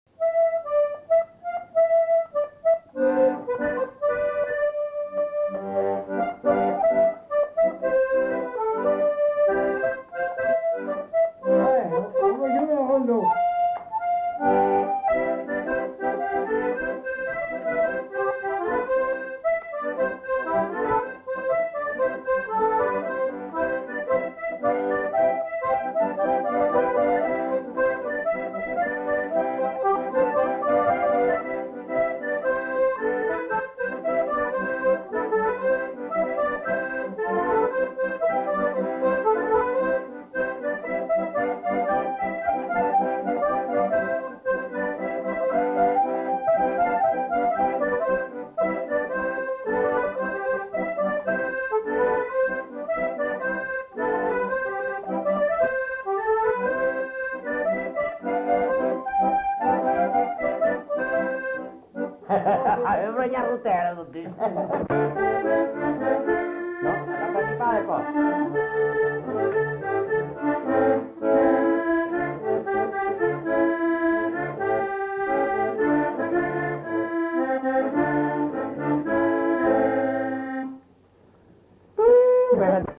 interprété à l'accordéon diatonique
enquêtes sonores